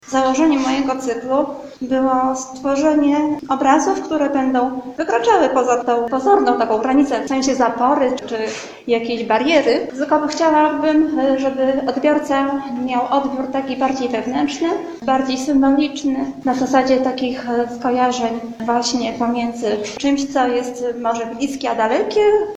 Podczas wernisażu tłumaczyła skąd wziął się tytuł wystawy: